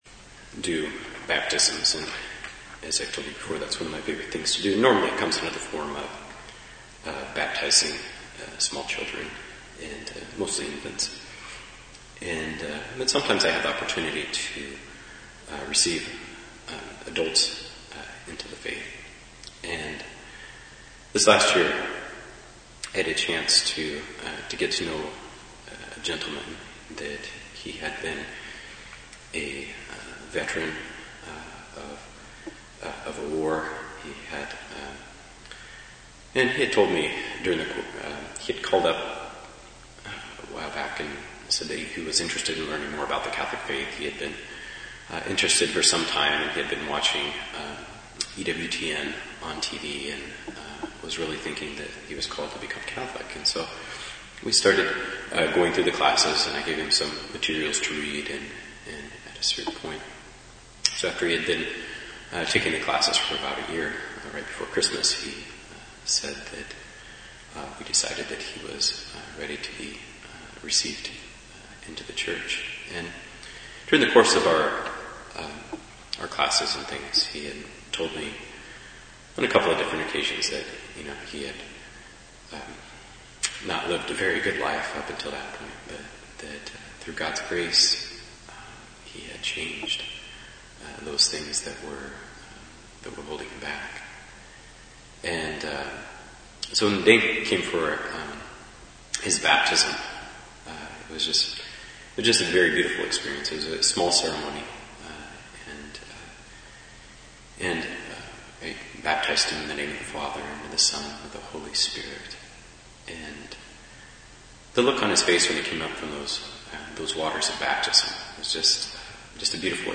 Homilies from 2013